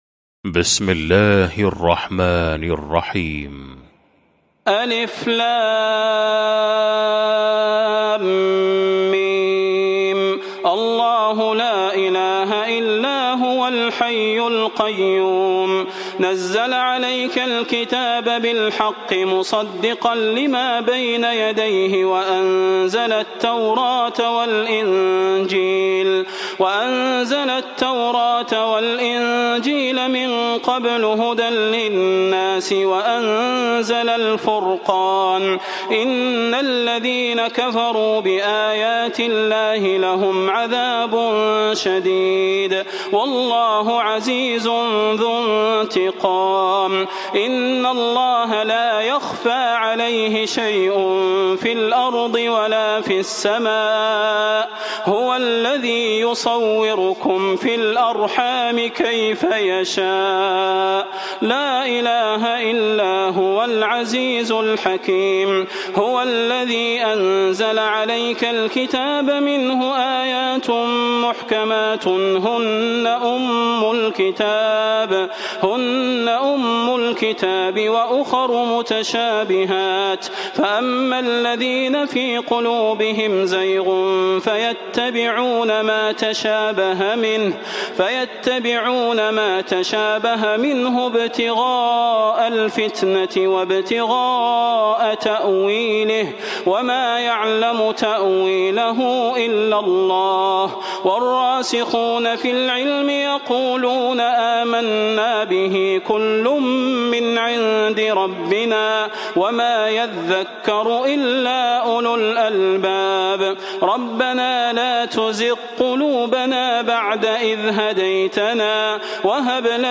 المكان: المسجد النبوي الشيخ: فضيلة الشيخ د. صلاح بن محمد البدير فضيلة الشيخ د. صلاح بن محمد البدير آل عمران